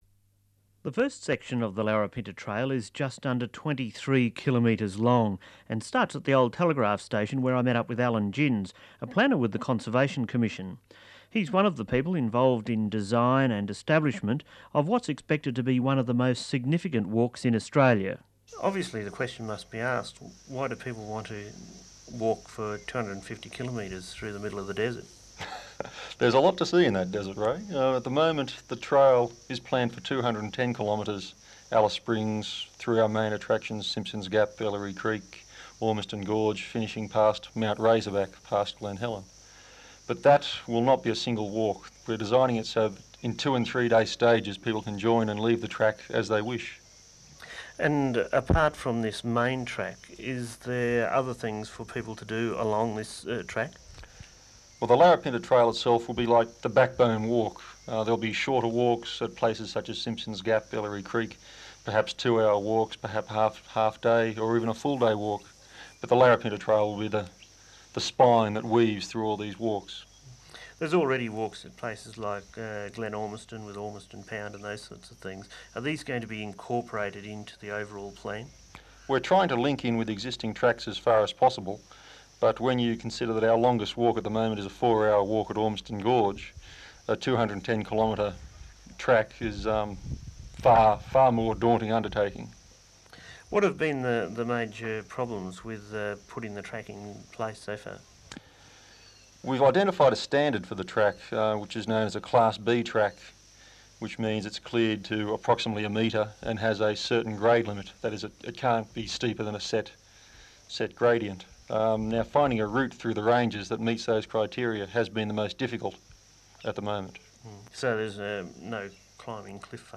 Taking a break on the Larapinta trail While many think of Central Australia as a desert, there is real beauty in this arid environment.